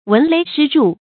聞雷失箸的讀法